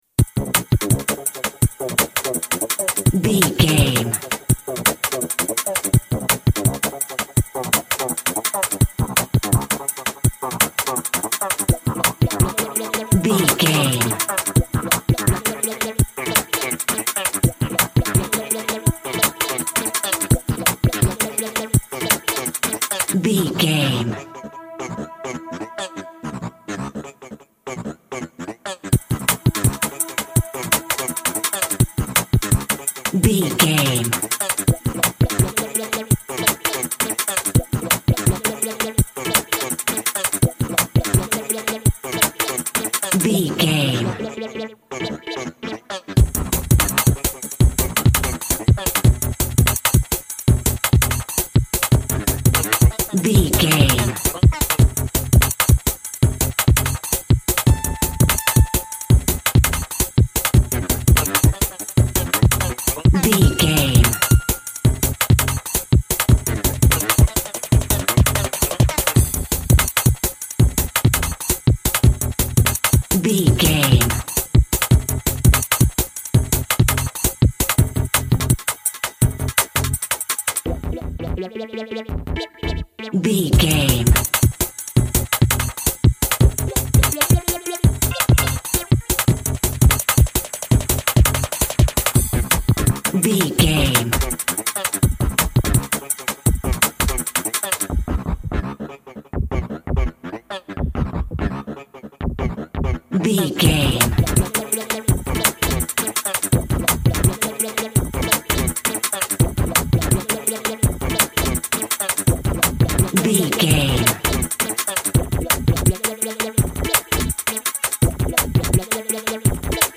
Drum and Bass Meets Techno.
Aeolian/Minor
hypnotic
industrial
driving
energetic
frantic
drums
synthesiser
synth lead
synth bass